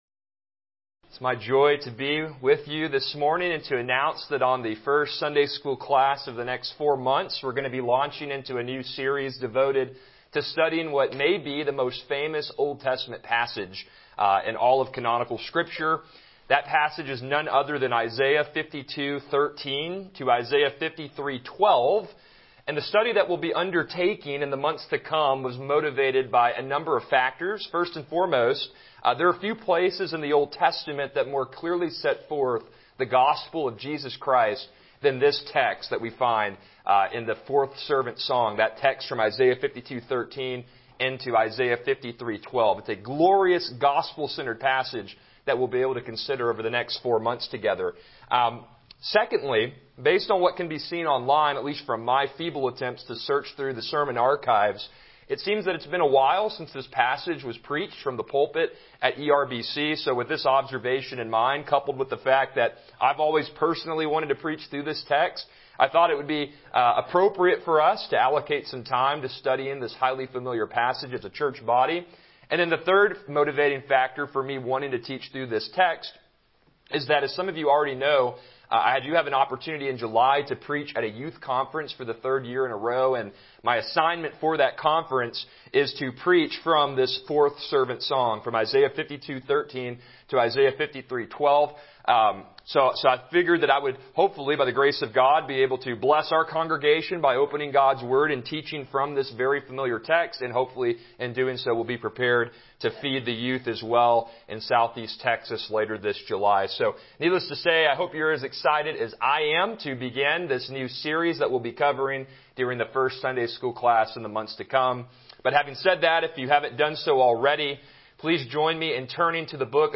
Passage: Isaiah 53:1-3 Service Type: Sunday School